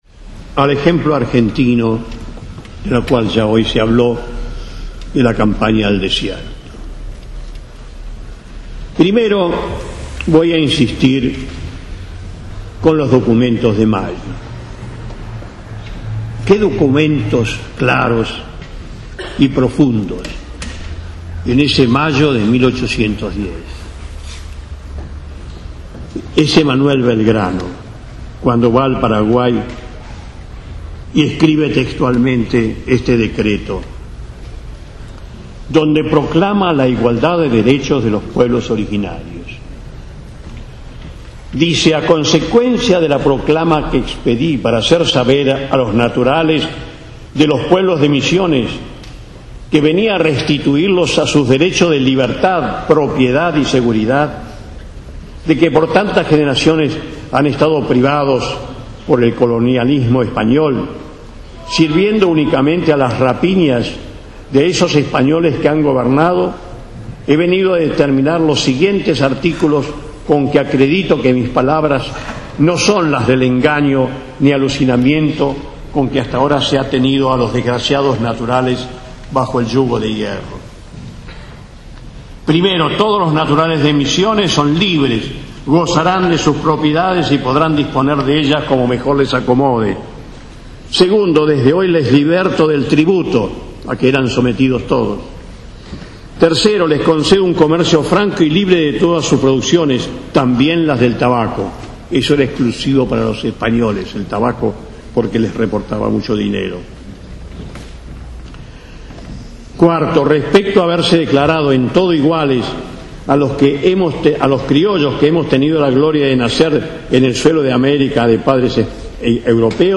La Agencia de Noticias Copenoa reproduce el audio de la exposición del destacado y comprometido historiador.
El historiador Osvaldo Bayer, analiza en una disertación el racismo contra los indígenas, desde la llegada de los españoles al nuevo mundo, y el continuismo de este, con la participación de obispos católicos, durante la época colonial. Habla de los secuestros y desaparición de personas en Argentina, entre otros temas.